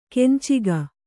♪ kenciga